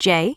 OCEFIAudio_en_LetterJ.wav